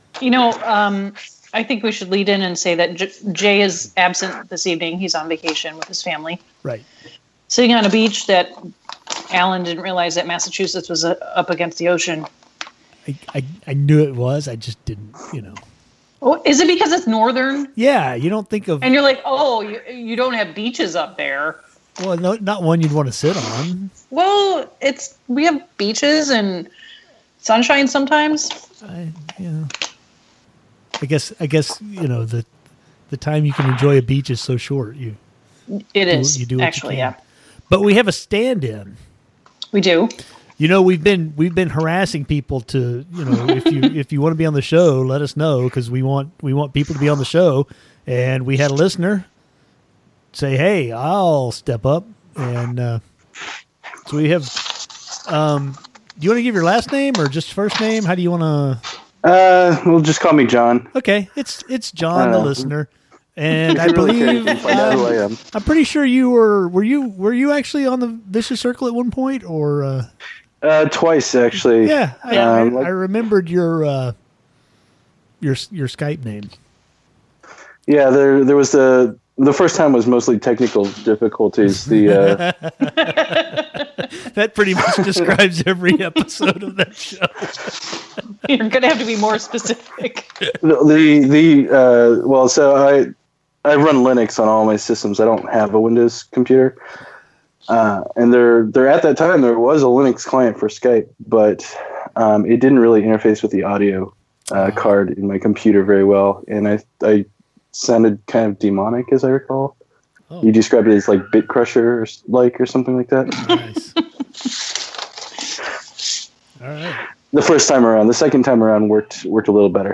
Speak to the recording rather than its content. Don’t worry, you’ll get your money’s worth because amazingly Audio Hijack kept recording Skype after my USB AD converter crapped out.